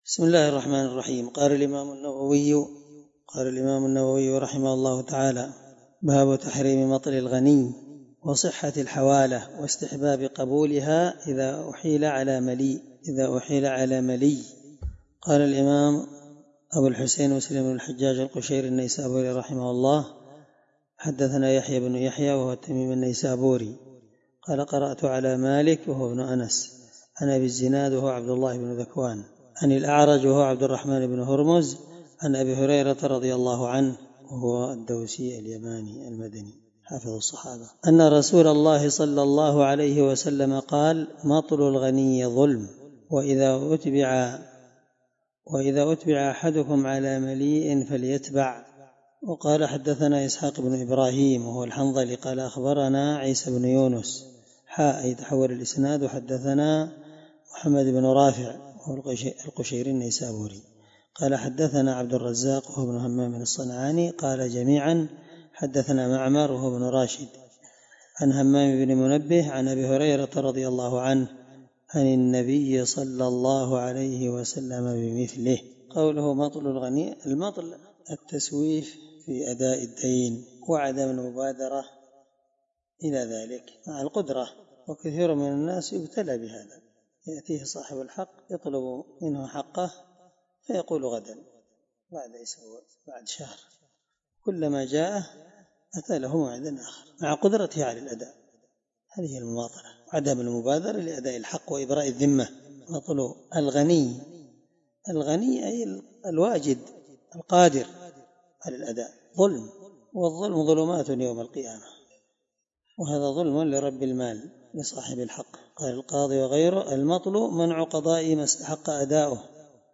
الدرس8من شرح كتاب المساقاة حديث رقم(1564) من صحيح مسلم